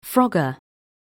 영국 [frɔ́gə]